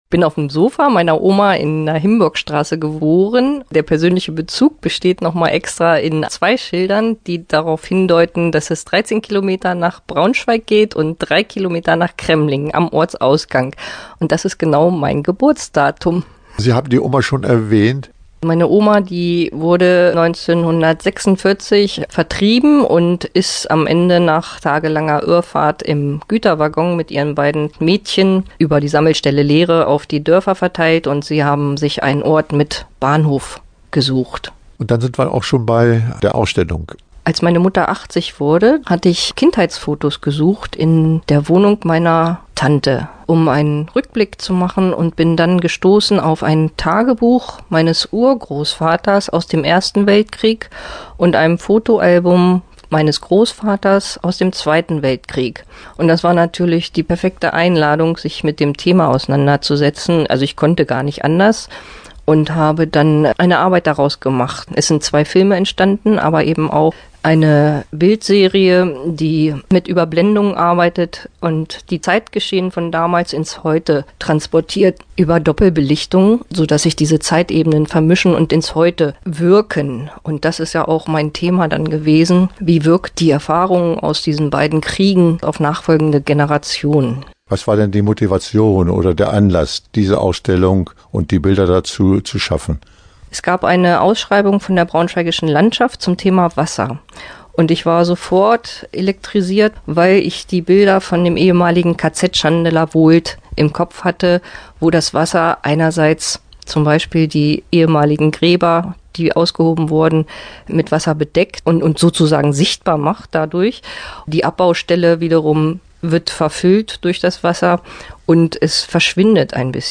Interview-Dunkle-Wasser.mp3